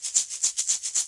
发现 " 摇蛋器03
描述：Shaker打击乐器自制